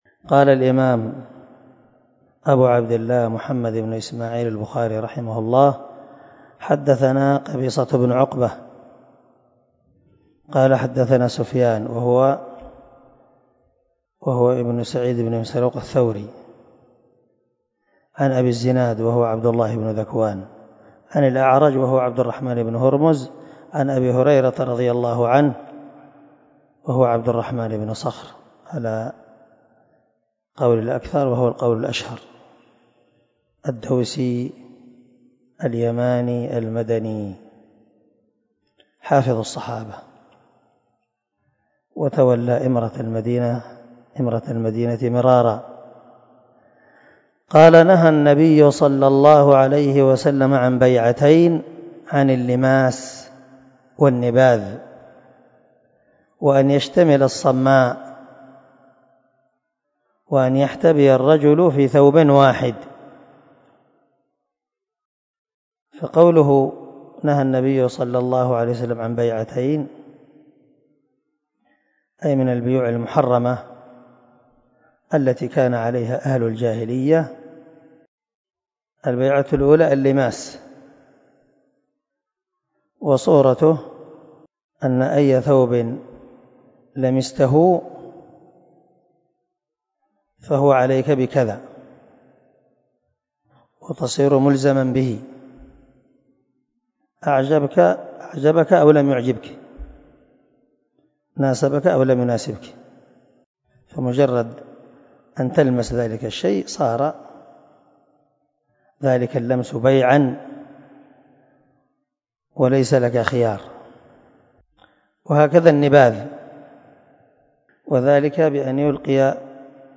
281الدرس 14 من شرح كتاب الصلاة حديث رقم ( 368 ) من صحيح البخاري